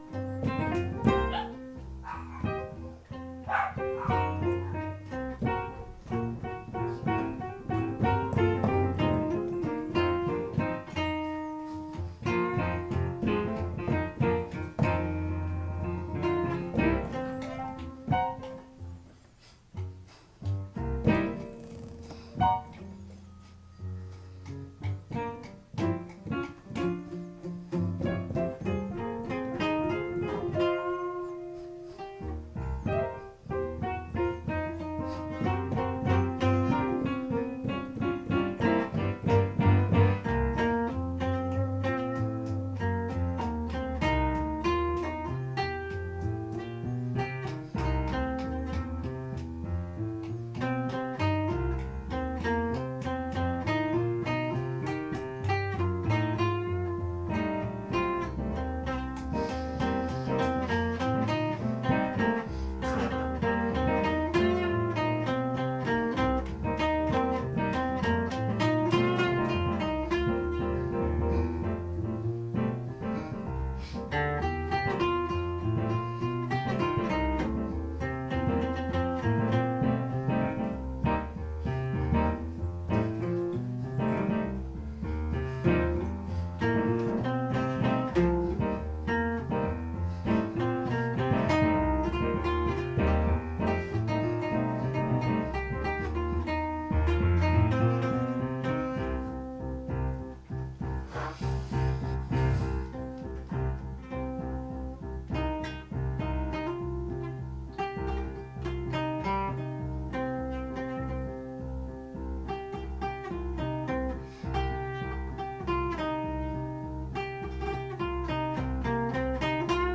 Blues into Ab7to Gmaj7